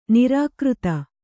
♪ nirākřta